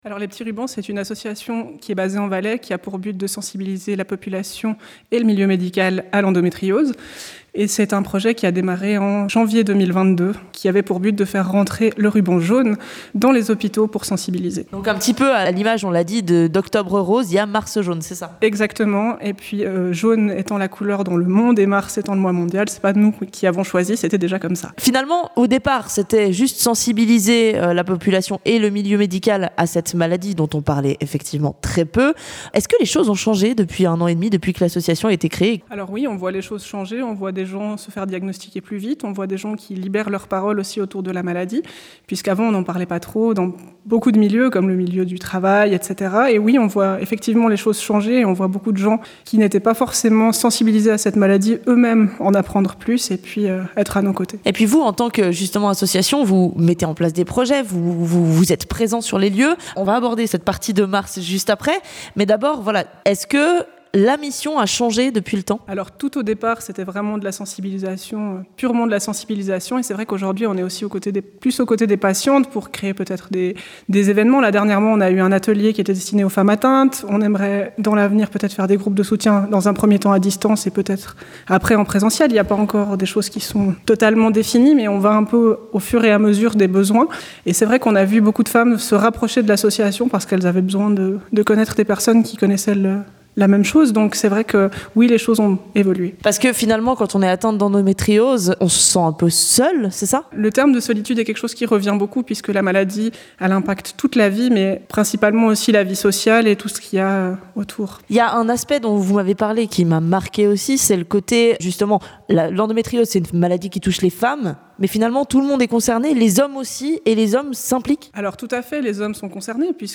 interview-les-petits-rubans-integrale.mp3